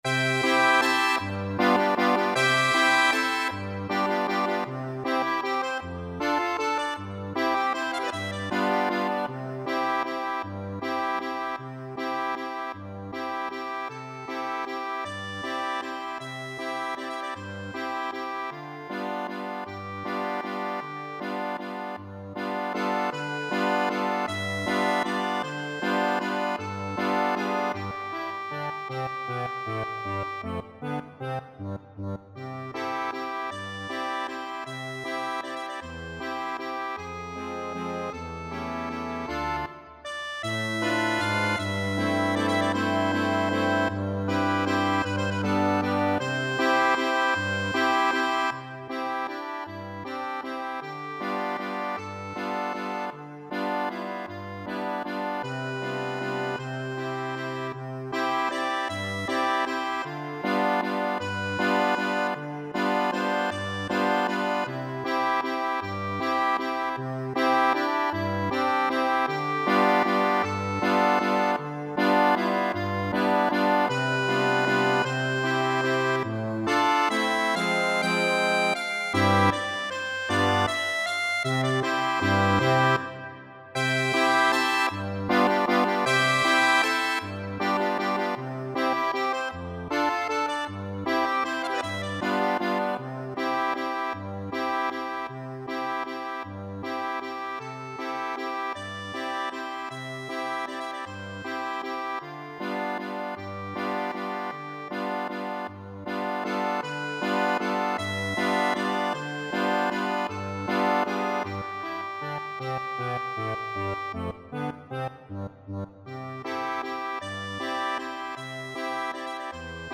6/8 (View more 6/8 Music)
Allegretto . = c.52
Accordion  (View more Intermediate Accordion Music)
Classical (View more Classical Accordion Music)